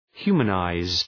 Προφορά
{‘hju:mə,naız}